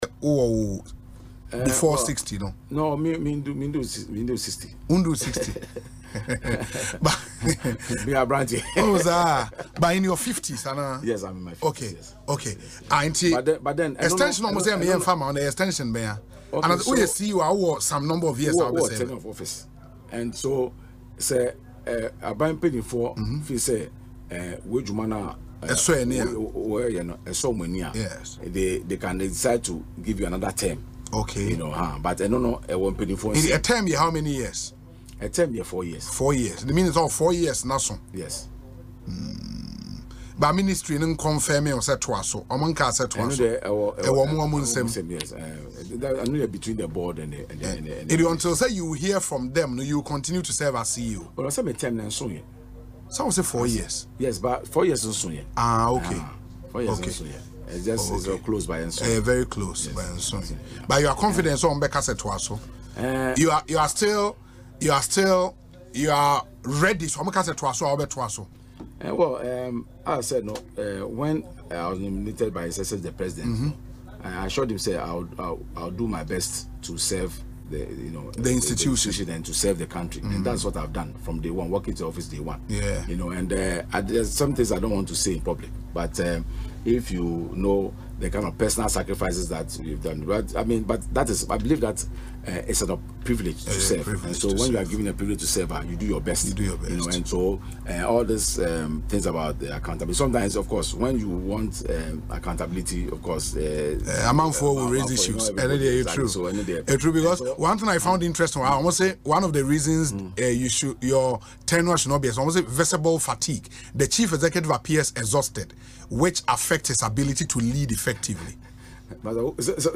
He made this known in an interview on Asempa FM’s Ekosii Sen programme Thursday, following concerns about his retirement.